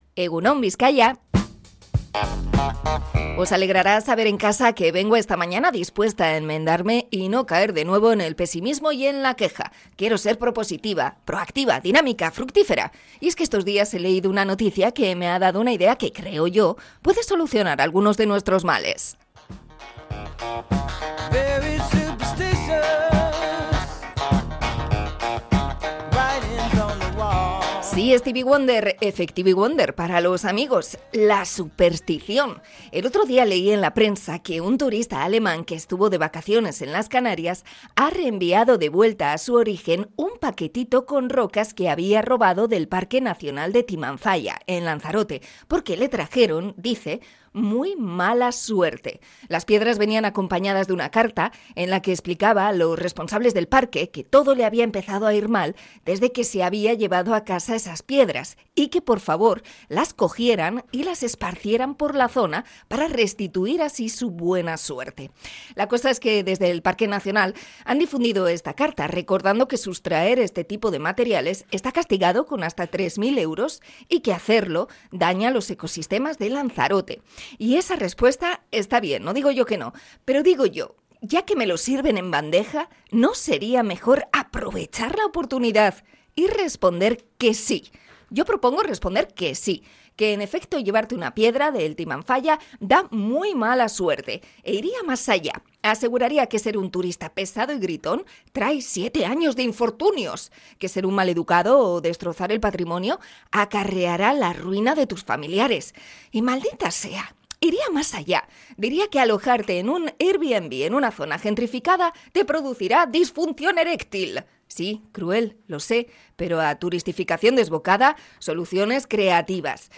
Comentario sobre las supersticiones y usarlas a nuestro favor